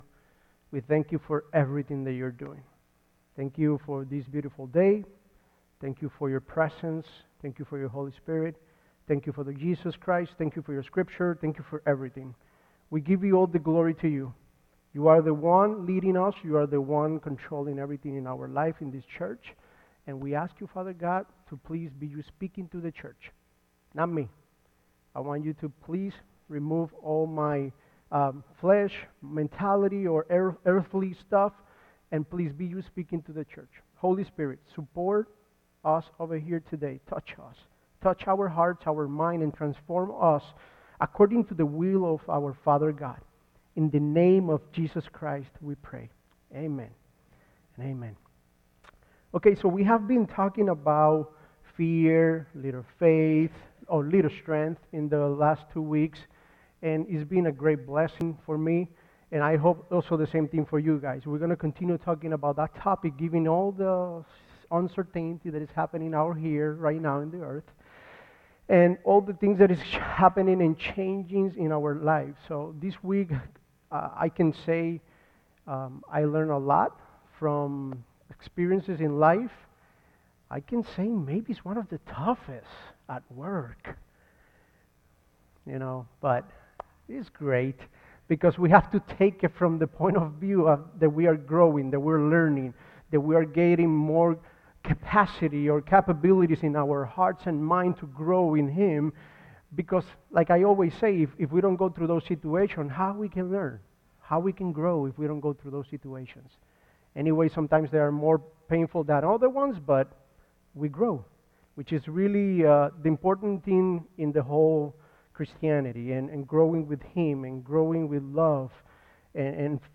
Sermons by FLC Elburn